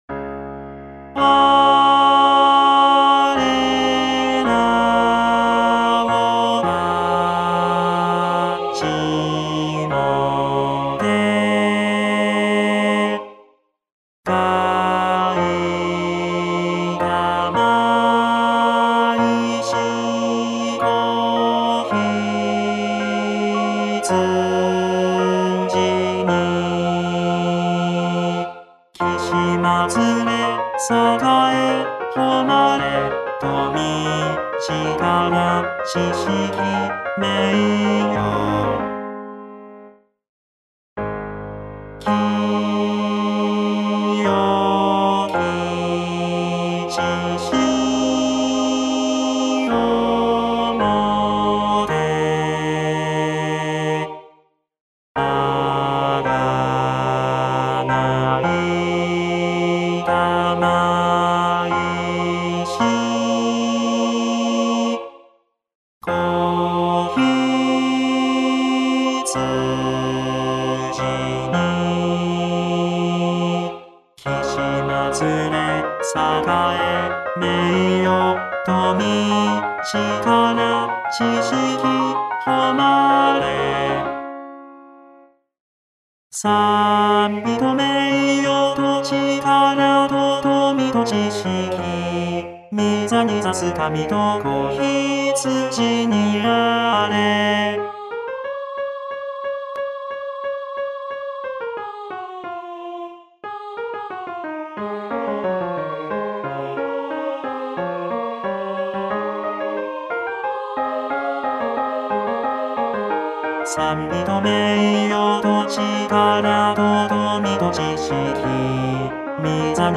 テノール（フレットレスバス音）